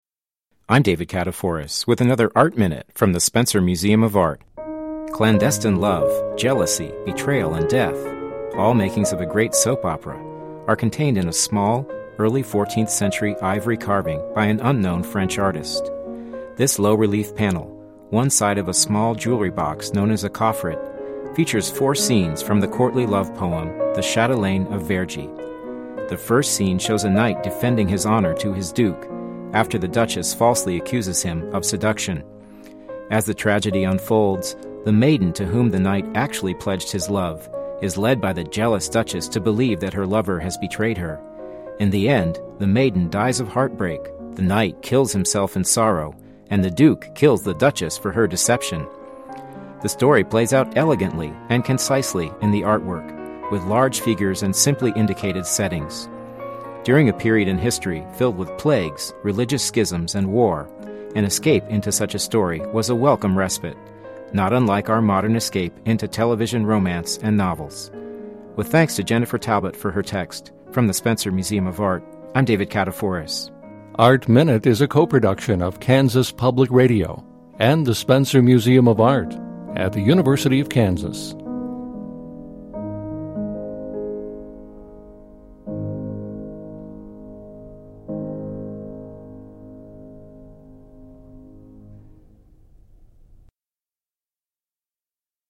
Didactic – Art Minute